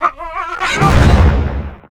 Pig_Attack_01.wav